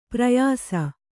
♪ prayāsa